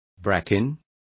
Also find out how helechos is pronounced correctly.